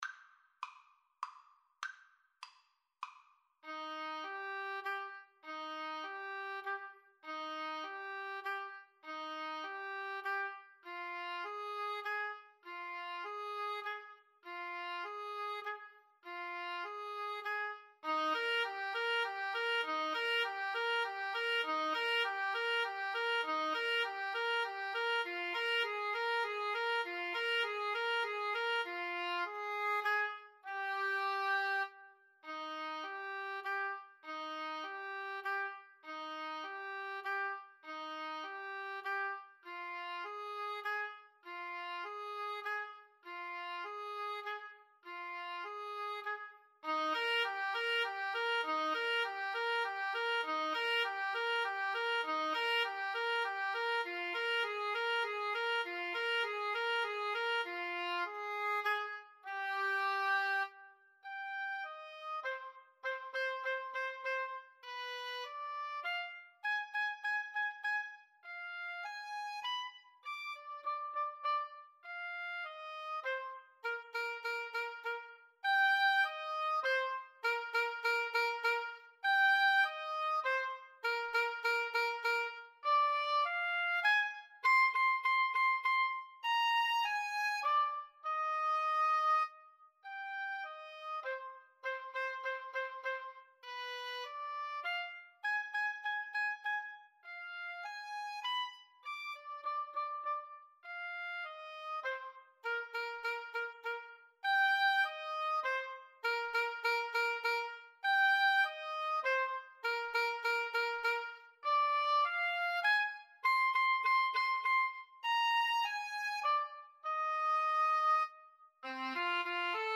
Free Sheet music for Oboe Duet
Eb major (Sounding Pitch) (View more Eb major Music for Oboe Duet )
3/4 (View more 3/4 Music)